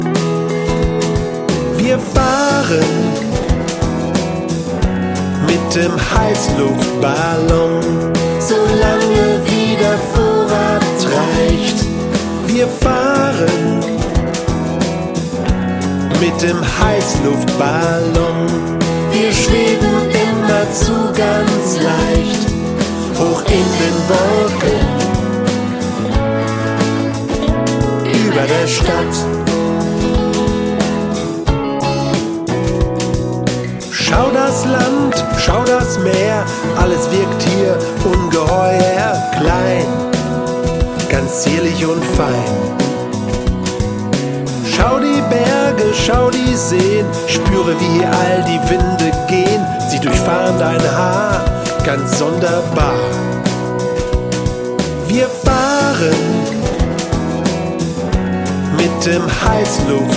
Mal swingend, mal rockig, mal Texte für den Kopf,
mal Rhythmen die in die Beine gehen...